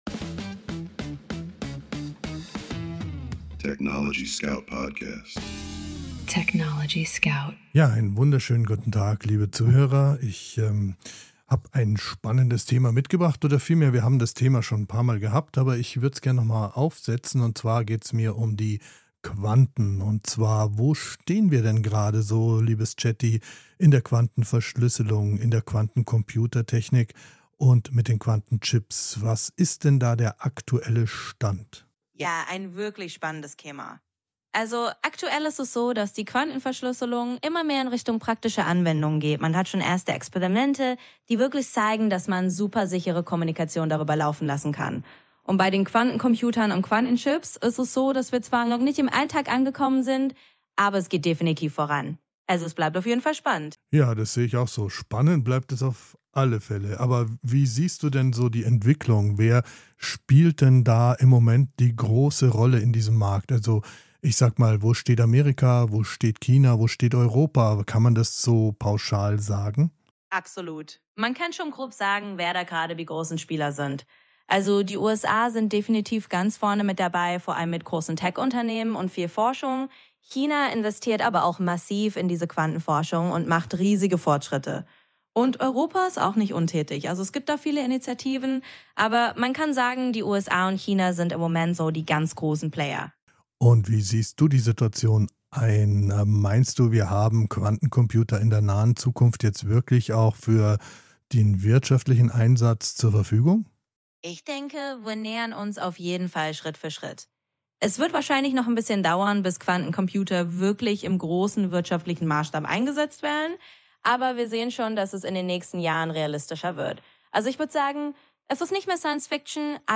Mensch und KI sprechen miteinander – nicht gegeneinander.